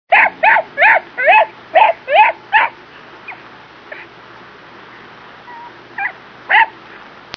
• Animal Ringtones